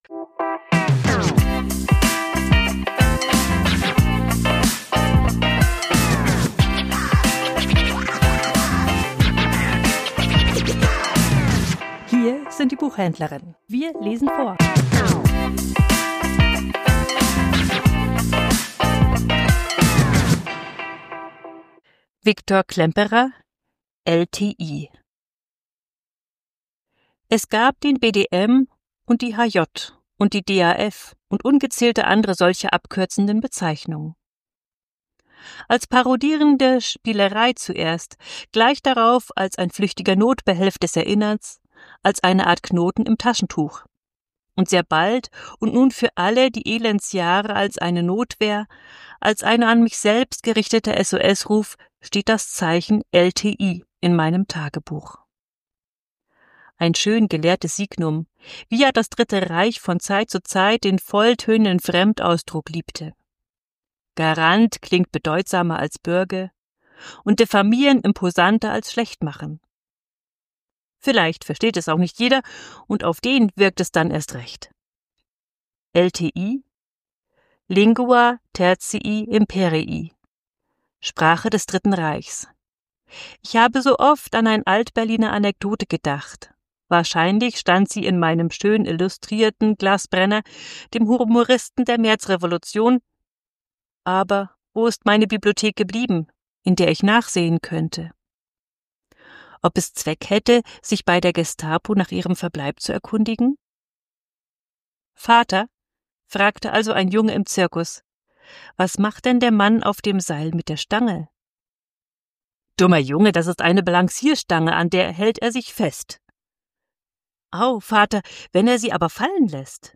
Vorgelesen: LTI ~ Die Buchhändlerinnen Podcast